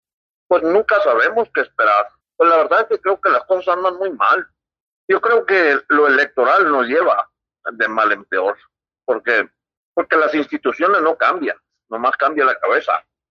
AUDIO: JULIÁN LEBARÓN, ACTIVISTA Y LÍDER DE LA COMUNIDAD LEBARÓN